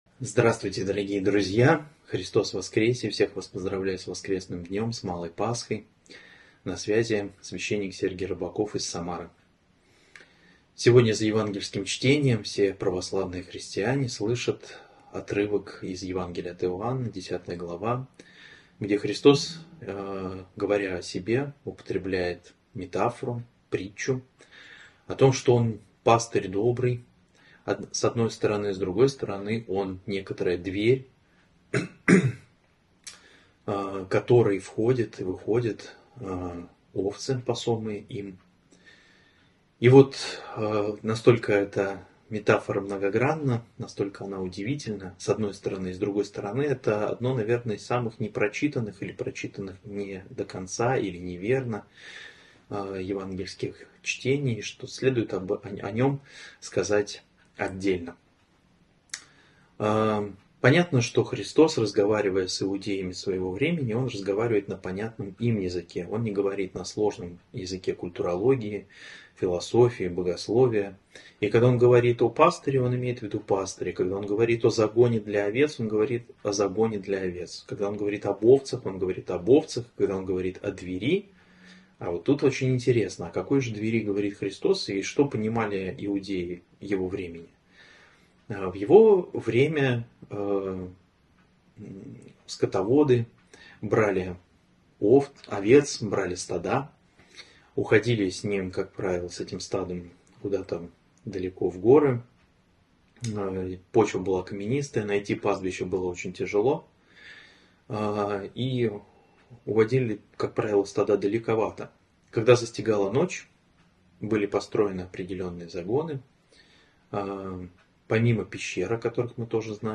«Проповедь» 10.11.2024